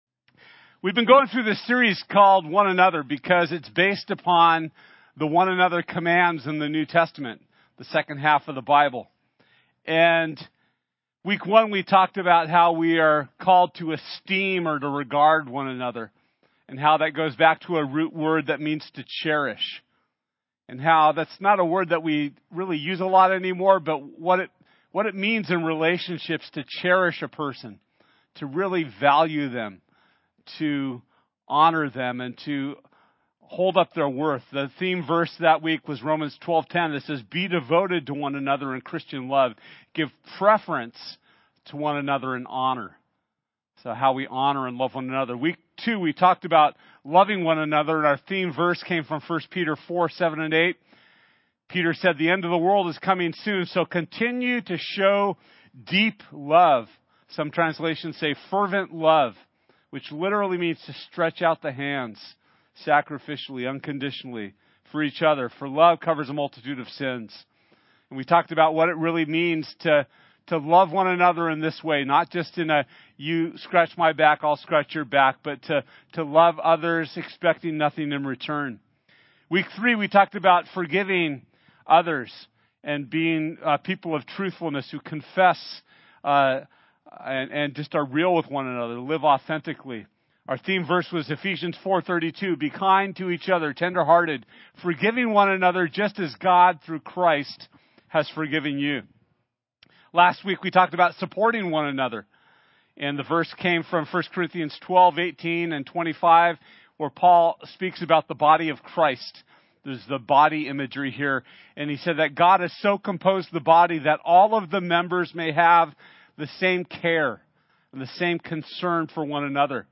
One Another Service Type: Sunday This Sunday we’ll be talking about what it means to Build One Another Up!